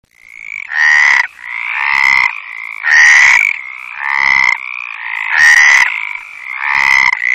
chant: sorte de "bouais" répété toutes les 2 secondes pour H. meridionalis, et un "kwouais, kwouais" ininterrompu pour H. arborea.
chant de H.meridionalis
chant meridionalis.mp3